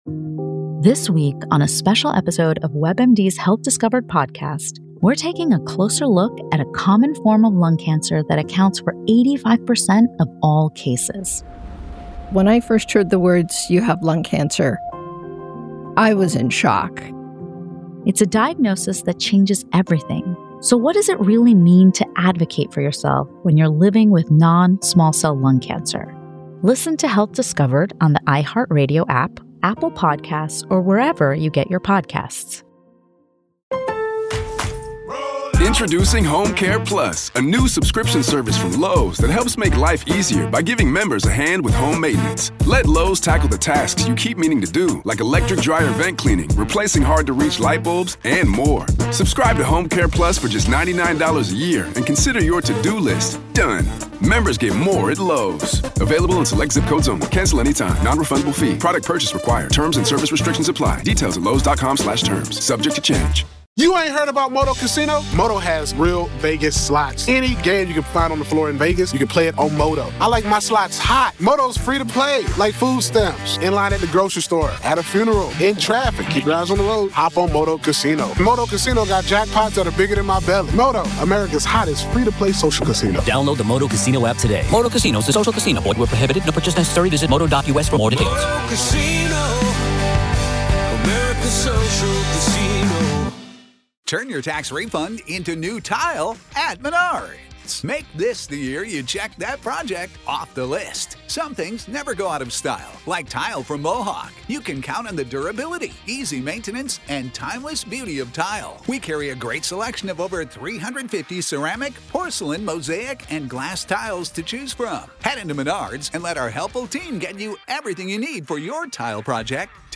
Our guest, a retired Army veteran who was stationed at Fort Riley, shares chilling stories of unexplained occurrences during their time living in a historic home on post. From missing items that reappeared under strange circumstances to a TV that uncontrollably changed channels, this episode explores the supernatural presence that seems to have a fondness for playing pranks.